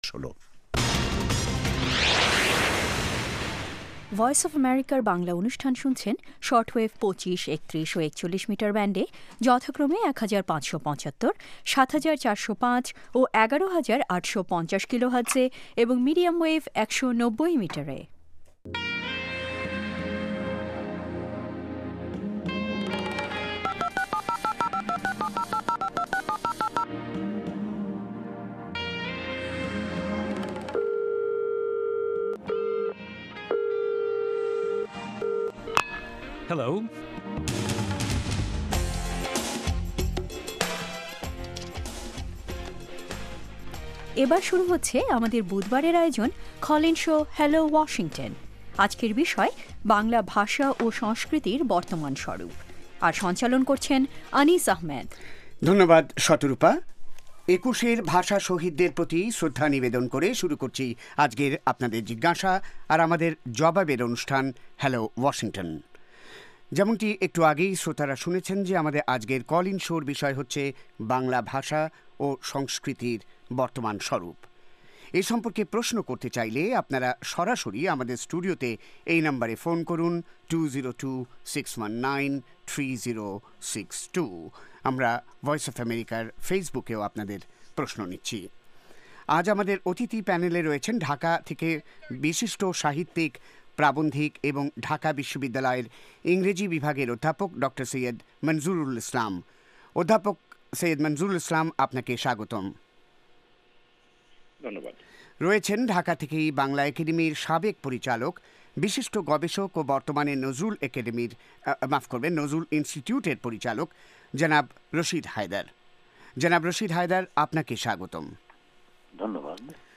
Hello Washington is VOA Bangla's flagship call-in show, providing in-depth analysis of major social and political issues in the United States and Bangladesh.